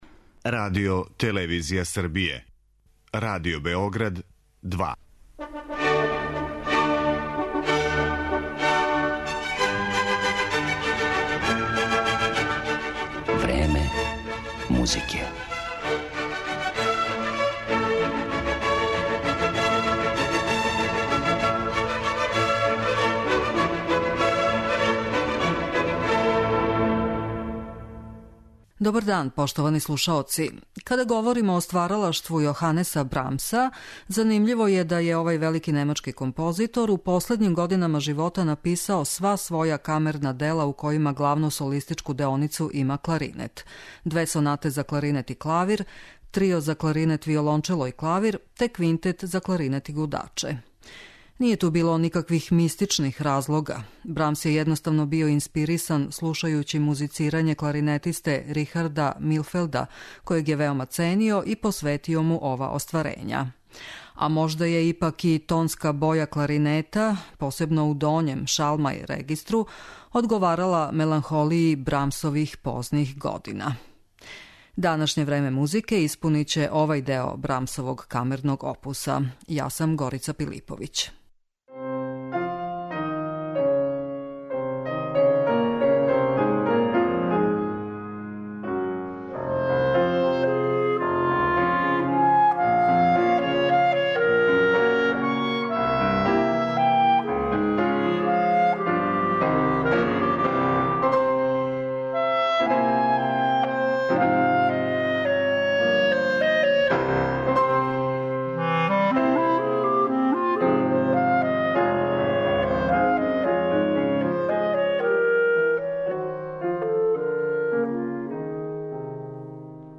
Јоханес Брамс је у последњим годинама живота написао сва своја камерна дела у којима главну солистичку деоницу има кларинет.